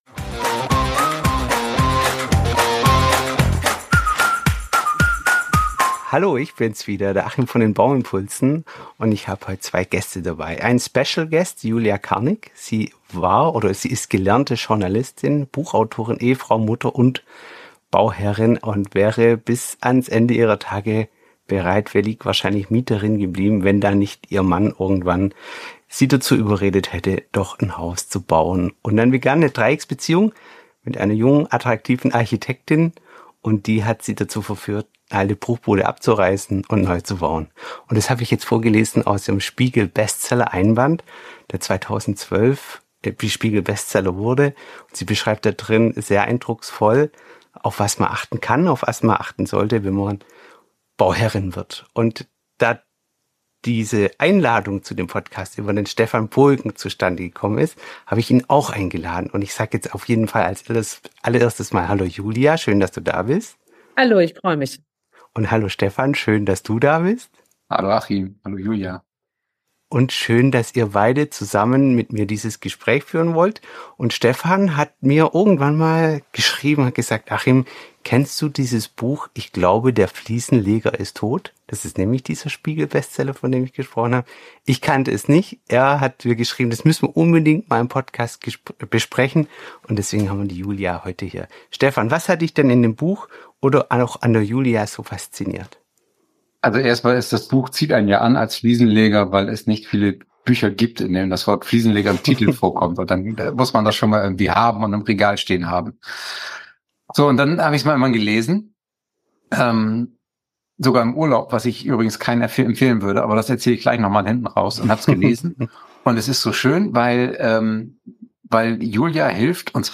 Die drei tauschen Gedanken über die Handwerker-Kundenbeziehung aus und betonen die essenzielle Rolle der Kommunikation.